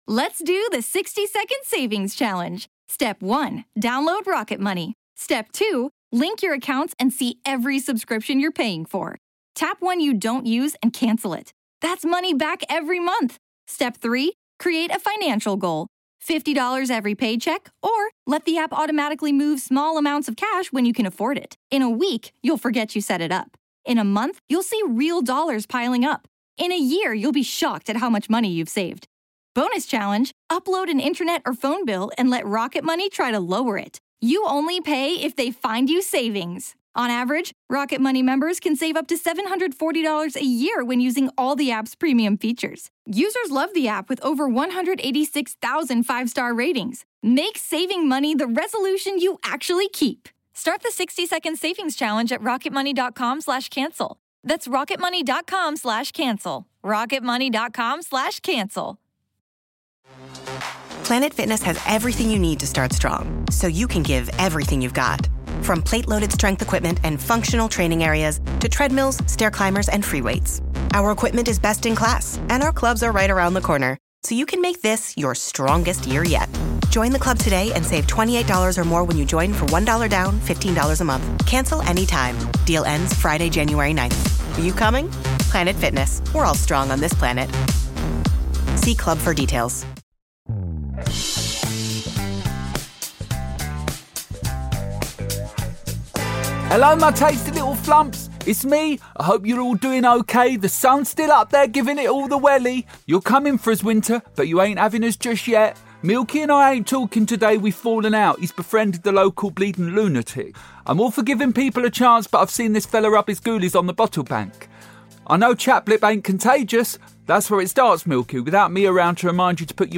Derry Girls' own Siobhan McSweeney joins Diane Chorley for this week's beautifully filled episode where they get a lot off their chest! They cover comedy, they cover anxiety and for the first time on Chatting with Chorley we discuss bleedin' COVID.